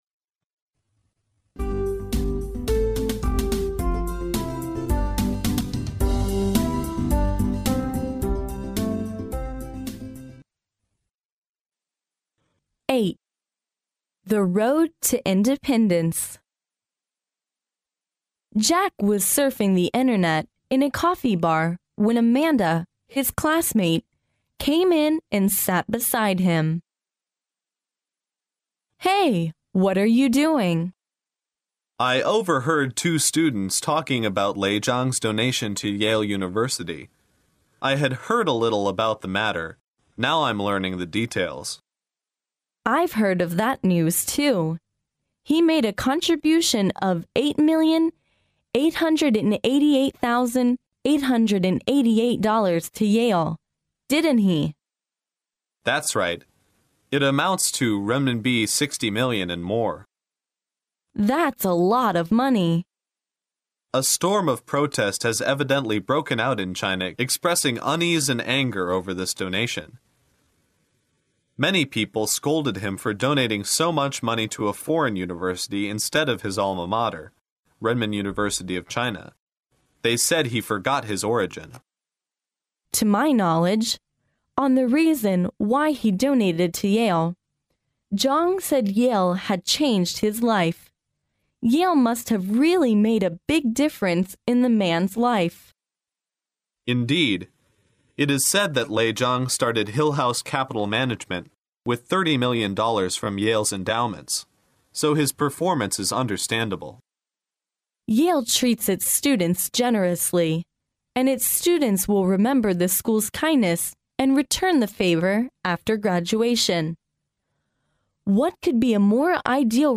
耶鲁大学校园英语情景对话08：自立之路（mp3+中英）